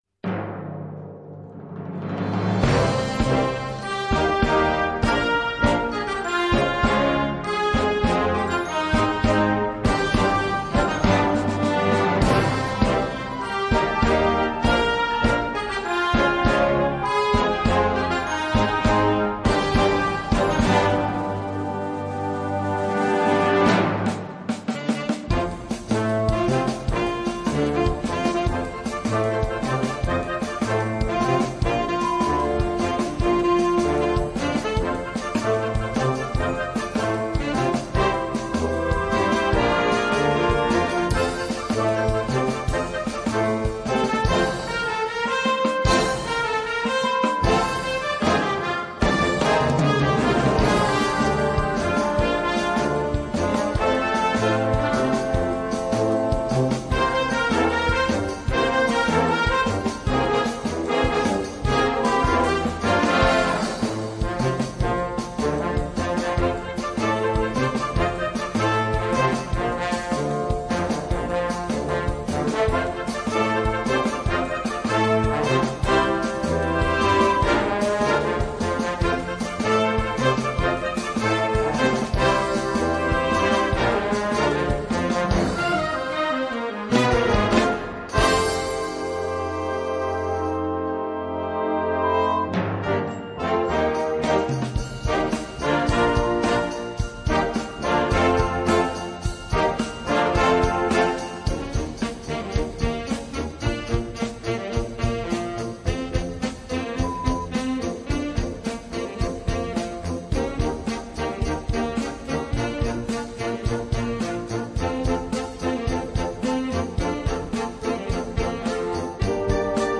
Gattung: Filmmusik
23 x 30,5 cm Besetzung: Blasorchester Tonprobe